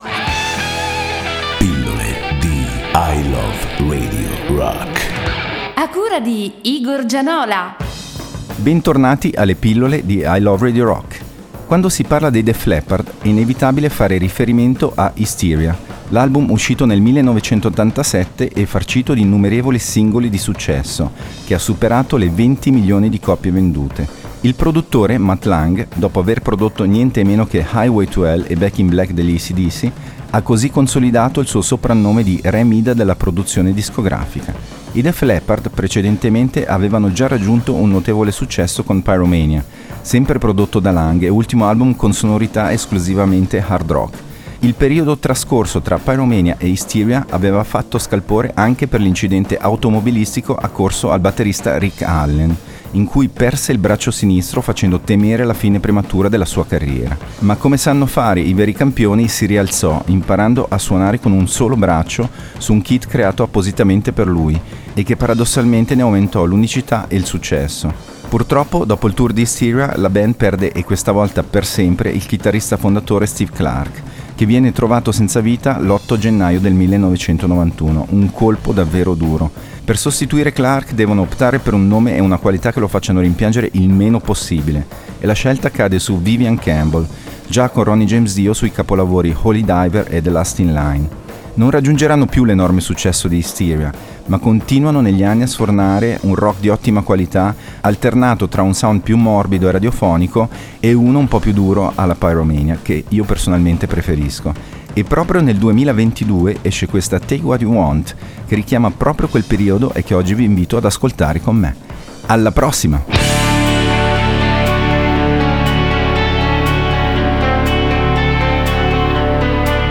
il programma dedicato alle sonorità hard rock e heavy metal che hanno fatto la storia. https